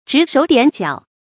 指手點腳 注音： ㄓㄧˇ ㄕㄡˇ ㄉㄧㄢˇ ㄐㄧㄠˇ 讀音讀法： 意思解釋： 猶指指點點。